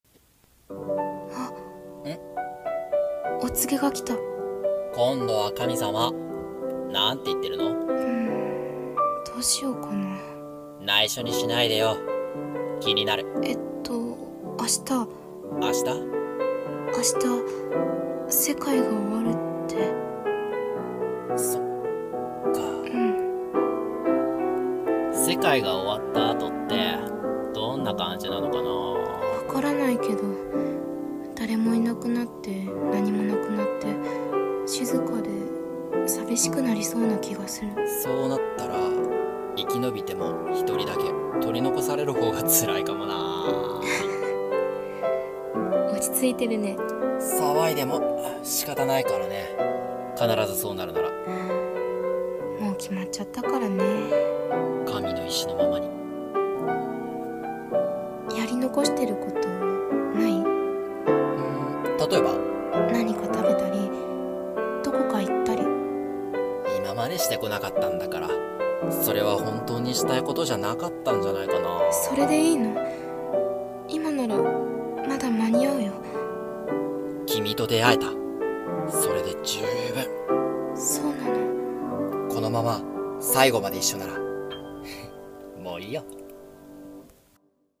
終末を、過ごそう。【二人声劇】